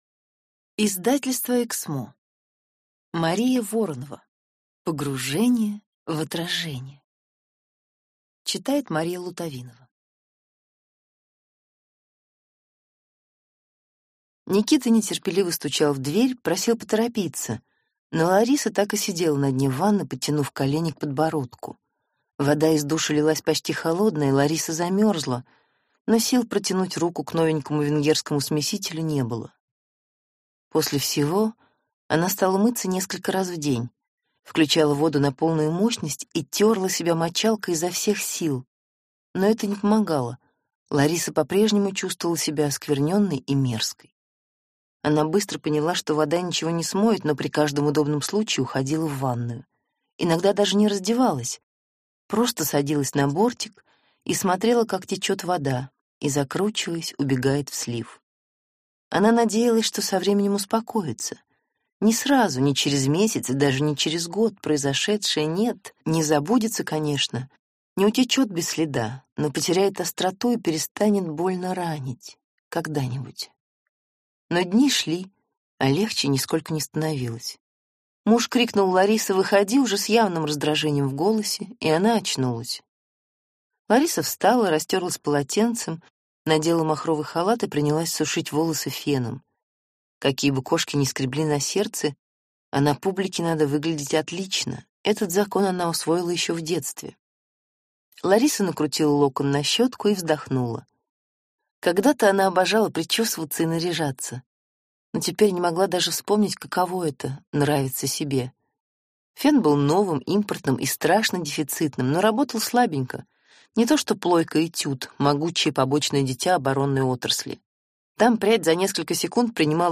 Аудиокнига Погружение в отражение | Библиотека аудиокниг